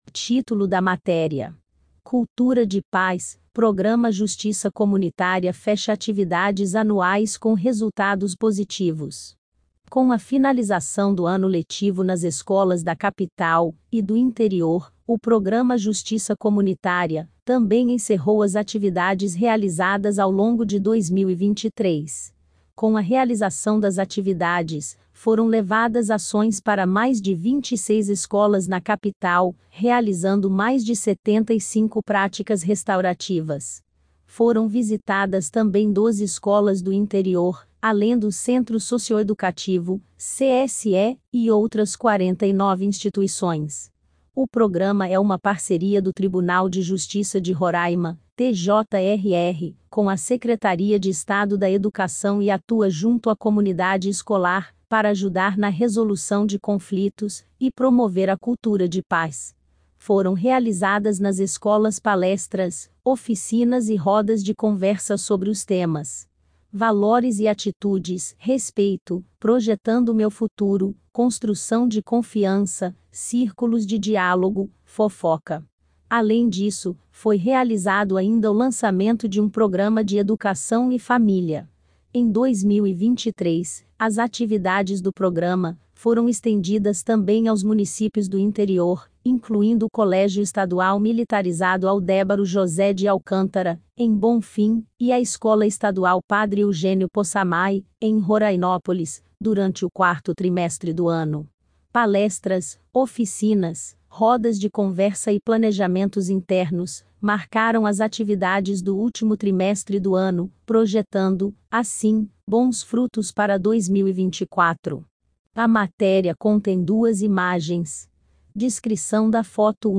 Cultura_de_paz_IA.mp3